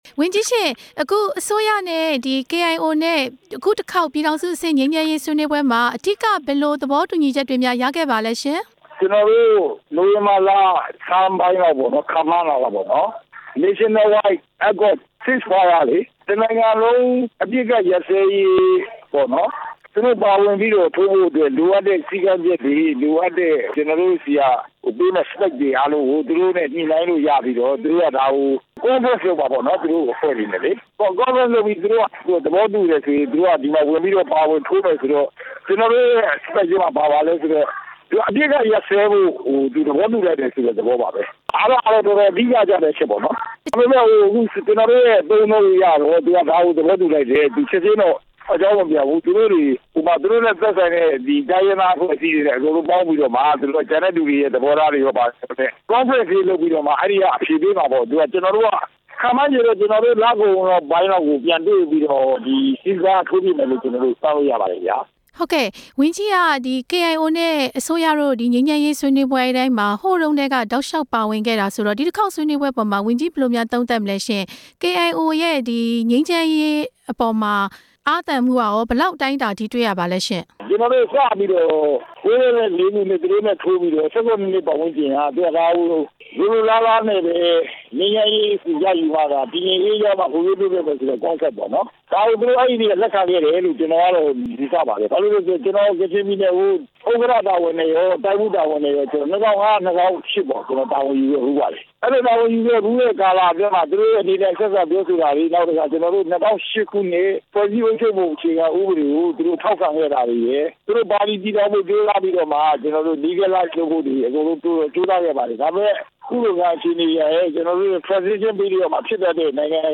ပြည်ထောင်စုဝန်ကြီး ဦးအုန်းမြင့်နဲ့ မေးမြန်းချက်